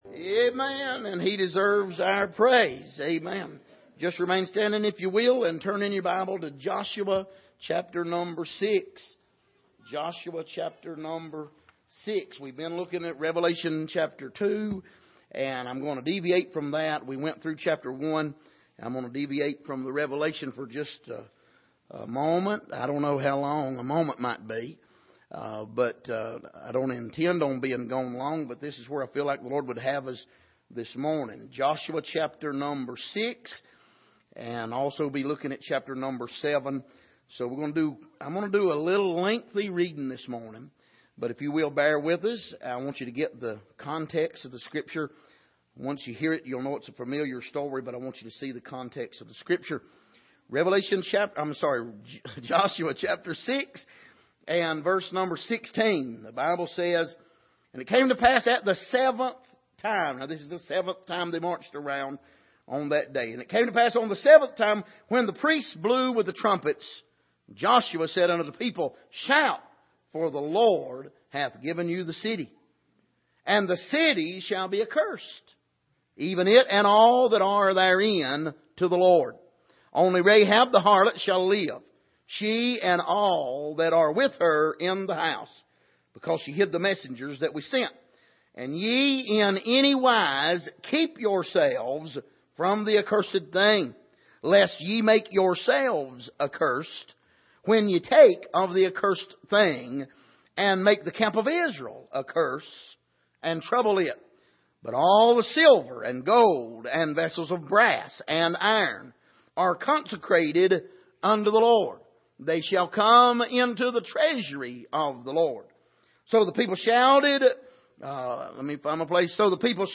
Passage: Joshua 6:16-20 Service: Sunday Morning